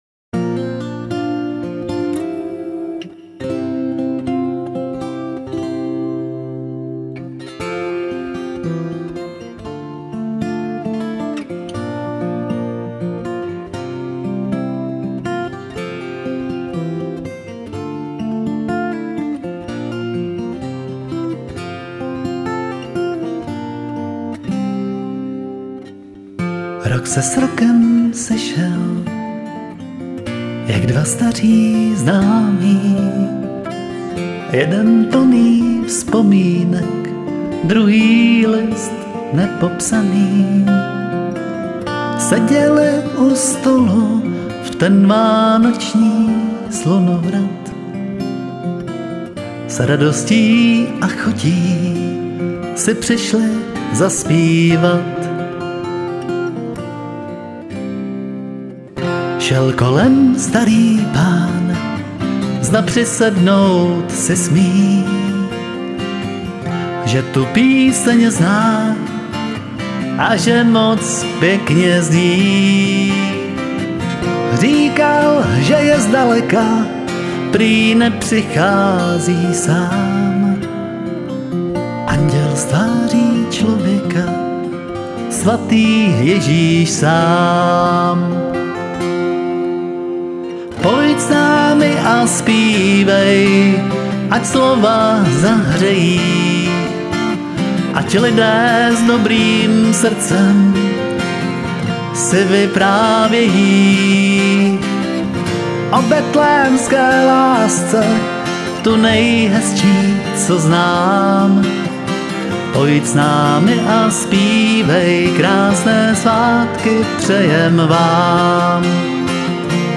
Vánoční píseň 2025
Píseň „Vánoční 2025“ je jednoduchá, melodická, a přitom nese hluboké poselství o lásce, světle,naději a skutečném bohatství.